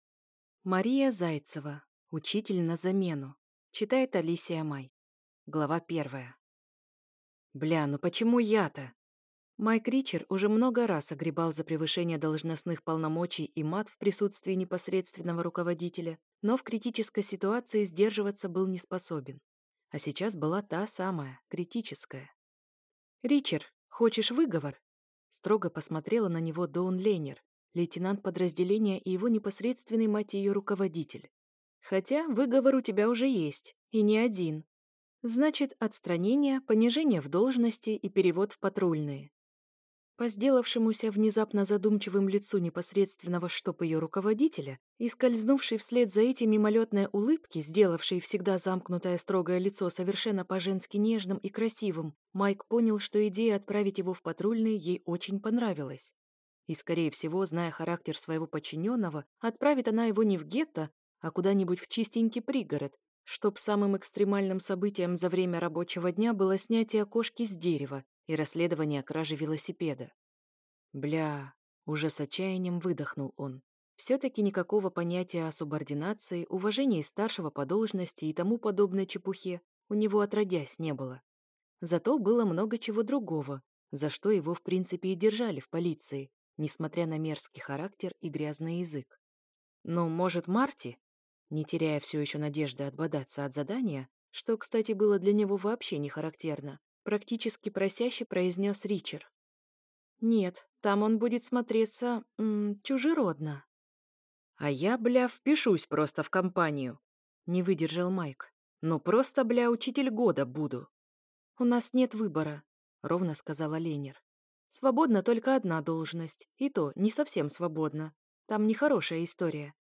Аудиокнига Учитель на замену | Библиотека аудиокниг